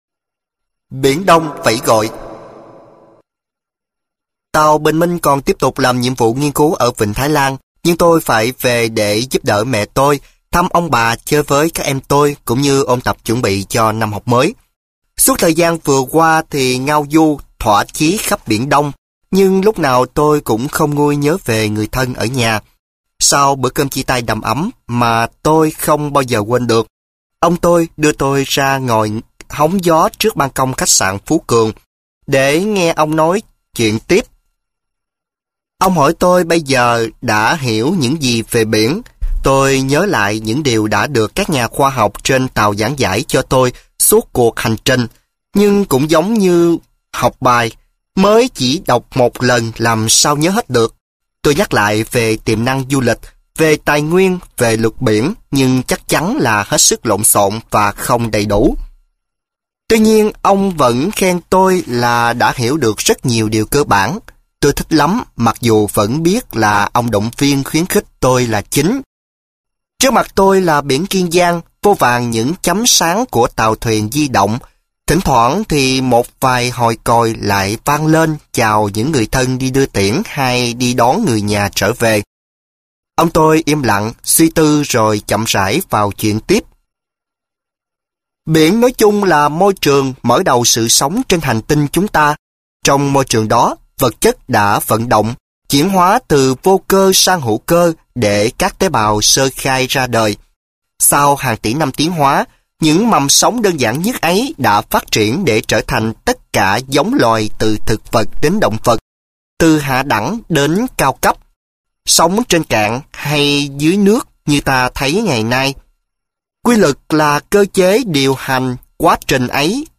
Sách nói Biển Đông Yêu Dấu - Trần Ngọc Toản - Sách Nói Online Hay